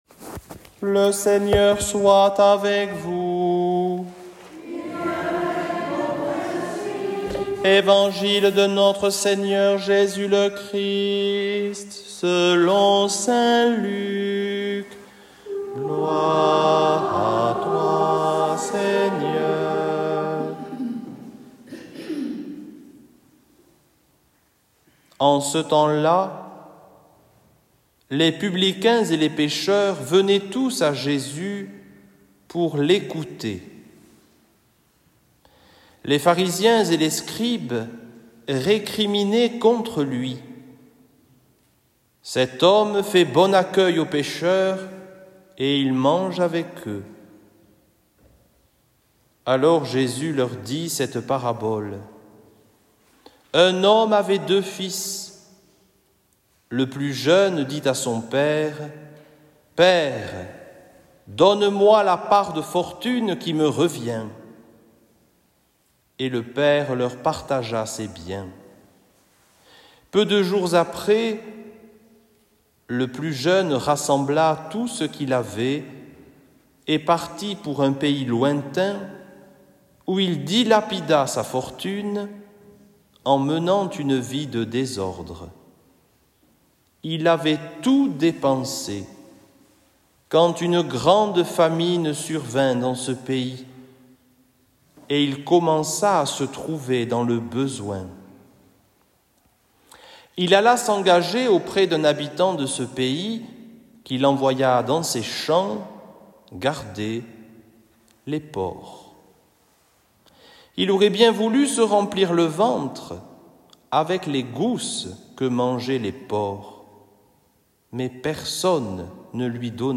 evangile-careme-4-2025.mp3